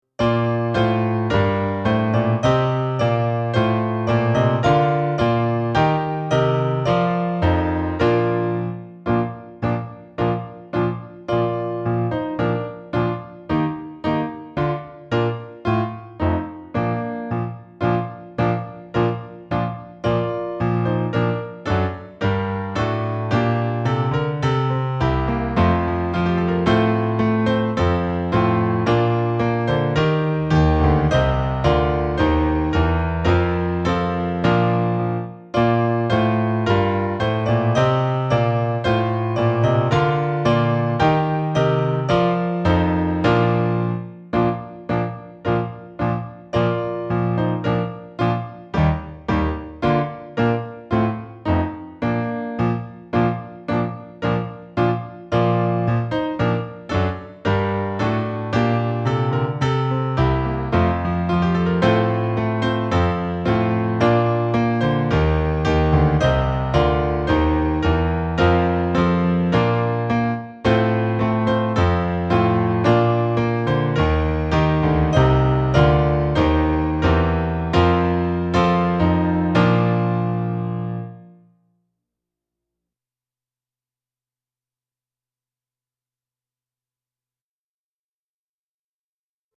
Christmas, Traditional, Popular carol
DIGITAL SHEET MUSIC - FLUTE OR OBOE SOLO & DUET